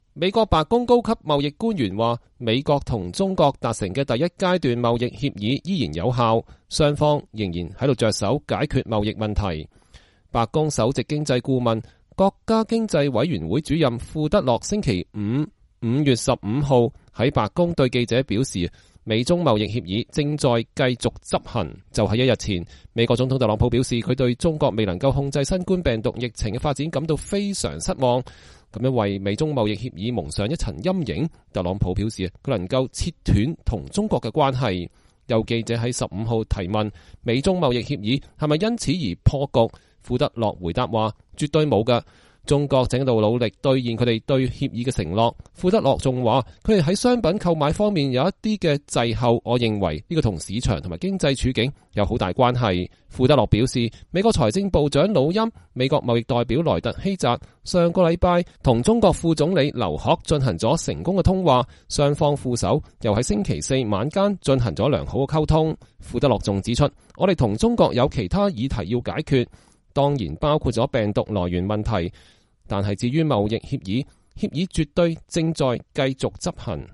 白宮首席經濟顧問庫德洛4月10日在白宮接受訪問。
白宮首席經濟顧問、國家經濟委員會主任庫德洛（Larry Kudlow）星期五（5月15日）在白宮對記者表示，美中貿易協議“正在繼續執行”。